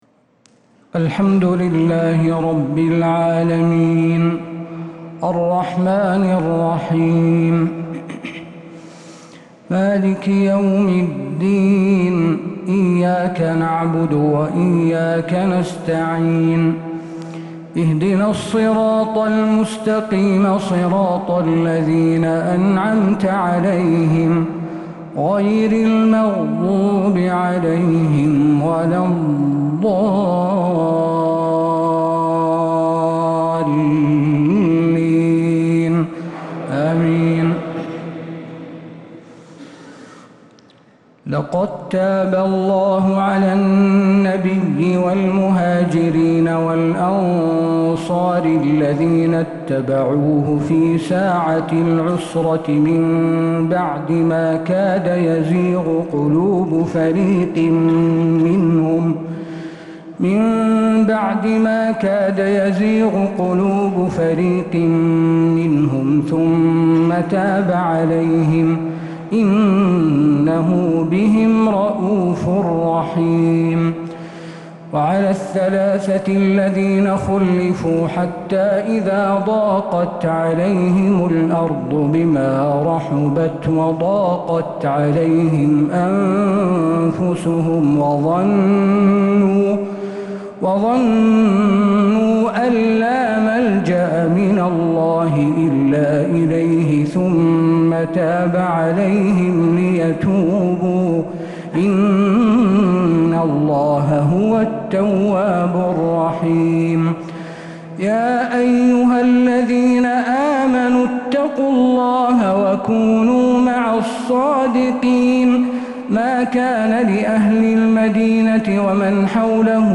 تراويح ليلة 14 رمضان 1446هـ من سورتي التوبة (117_129) و يونس (1-25)| Taraweeh 14th niqht Surat At-Tawba and Yunus 1446H > تراويح الحرم النبوي عام 1446 🕌 > التراويح - تلاوات الحرمين